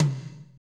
TOM F S H11L.wav